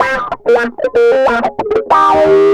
Track 13 - Distorted Guitar Wah 04.wav